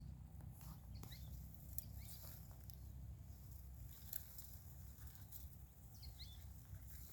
Azara´s Spinetail (Synallaxis azarae)
Location or protected area: Yerba Buena - Reserva Experimental Horco Molle
Detailed location: Jardín Botánico Horco Molle
Condition: Wild
Certainty: Observed, Recorded vocal